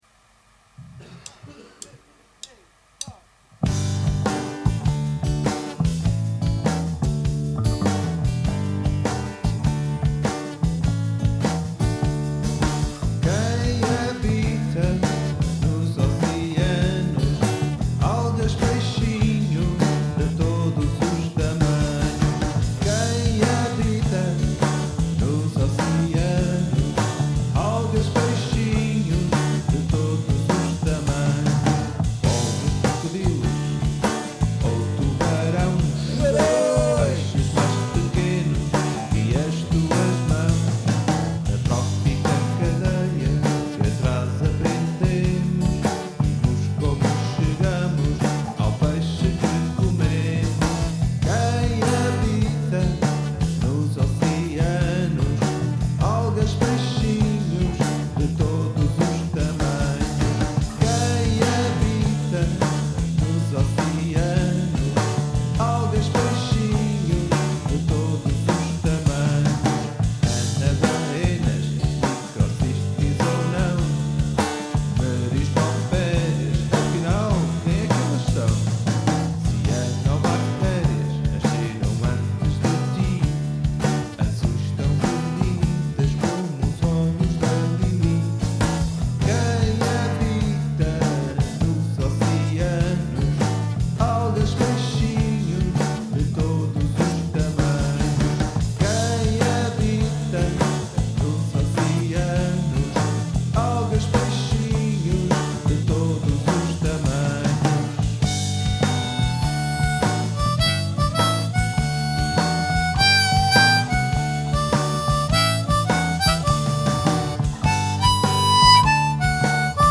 Versão integral: